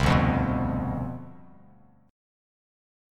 CM7sus2sus4 chord